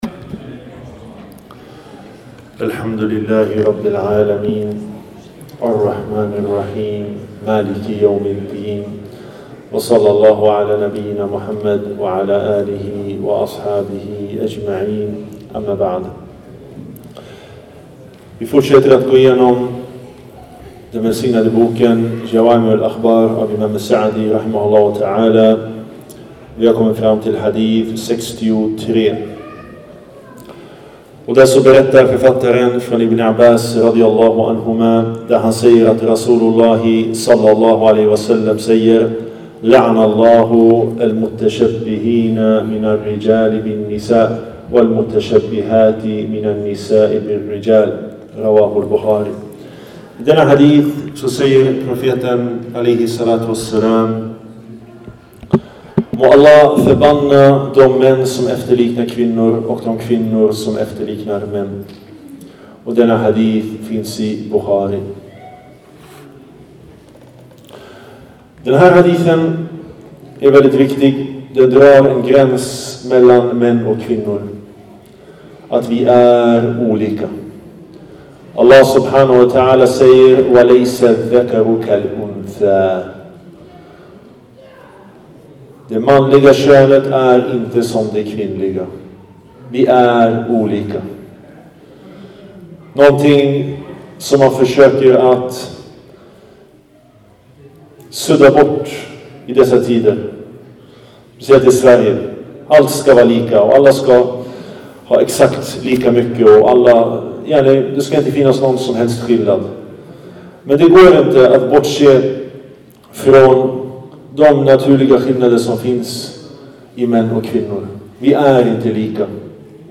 En föreläsning